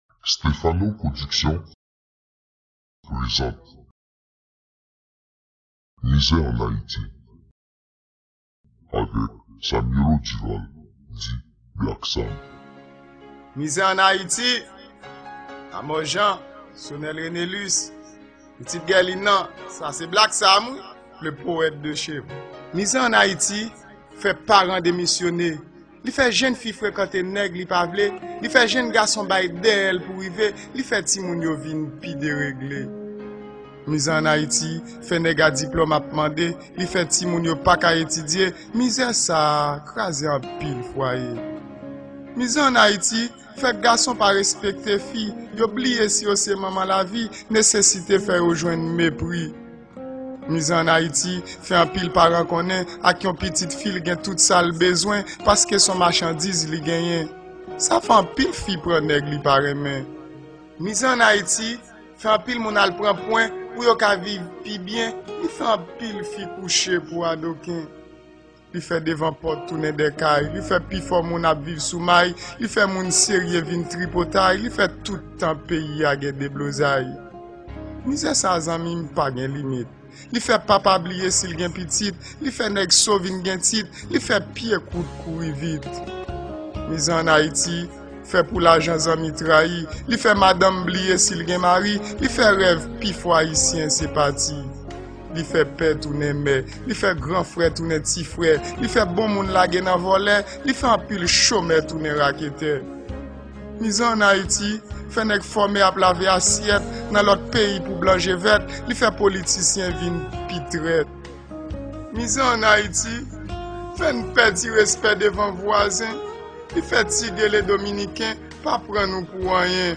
Genre: Slam